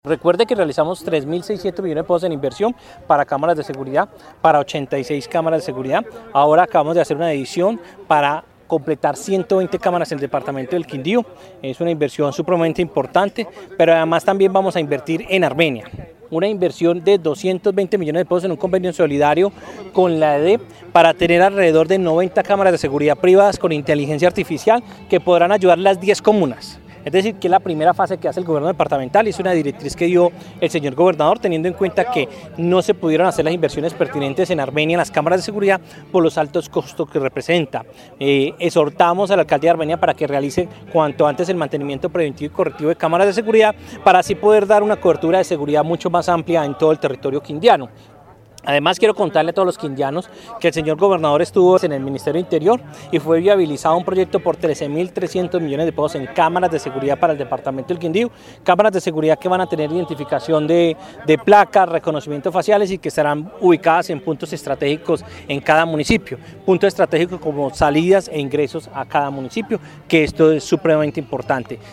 Secretario del Interior